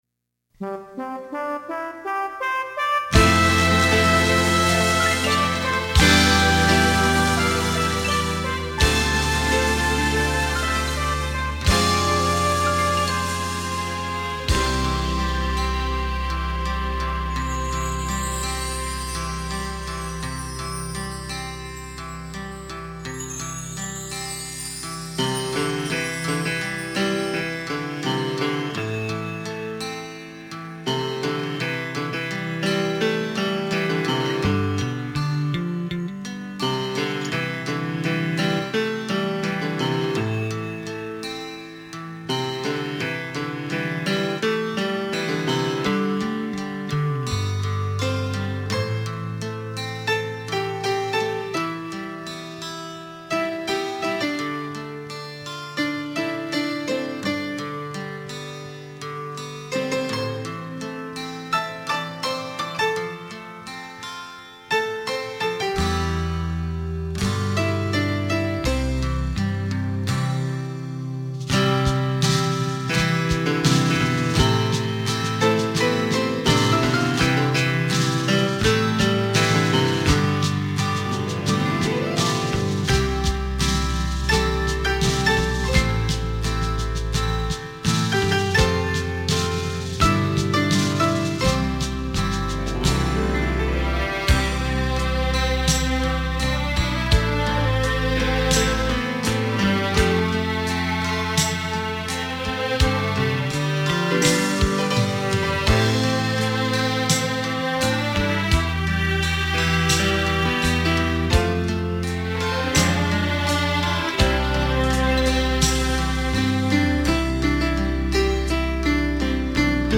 浪漫钢琴&浪漫小提琴
钢琴的琴键与小提琴的琴弦
交织出浪漫美丽的生活情趣